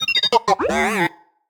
happy1.ogg